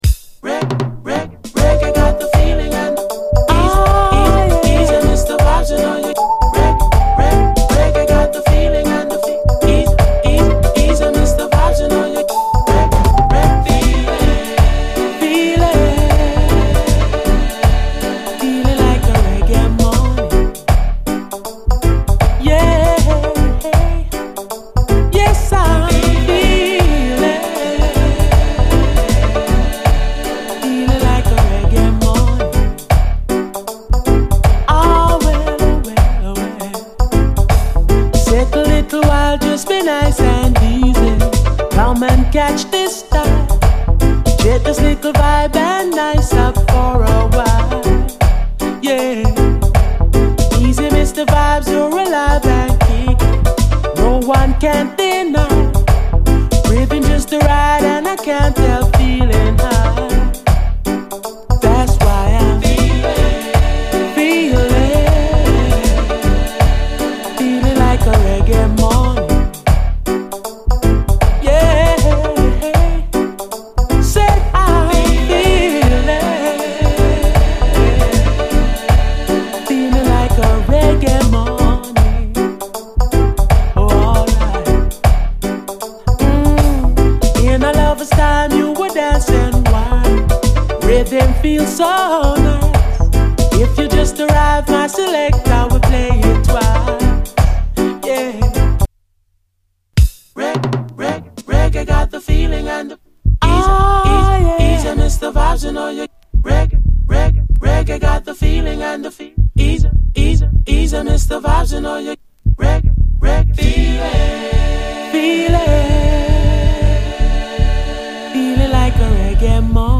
ファンキーに打ったビートとド派手ブラスが炸裂するファンキー＆グルーヴィー・ソウル満載！
ド迫力ブラスが炸裂するイントロの破壊力で一撃KOされるキラー・スウィート・ソウルです！